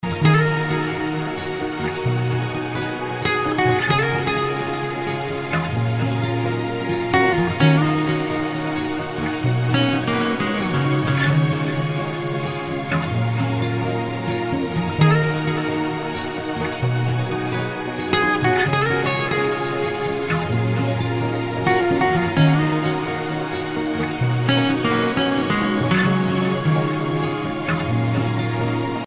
• Качество: 256, Stereo
спокойные
chillout
романтические
Спокойная расслабляющая игра гитары